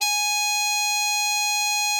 bari_sax_080.wav